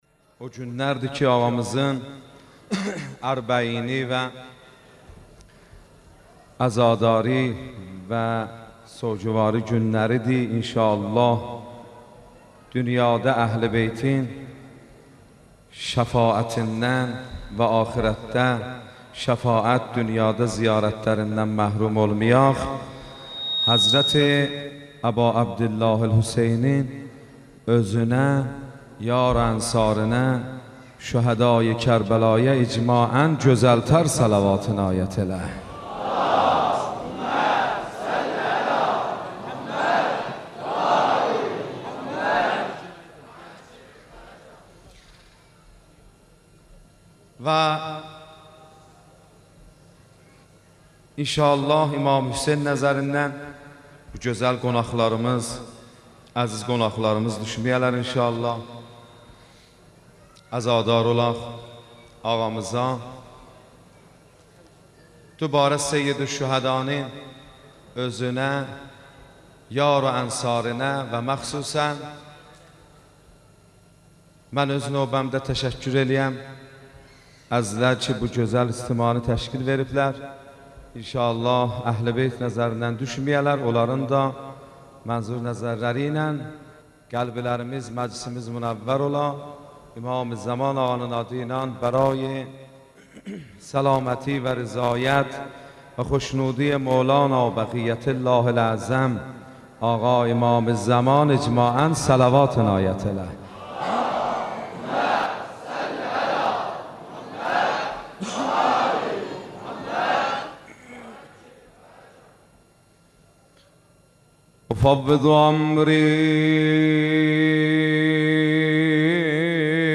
روضه اربعین
سینه زنی اربعین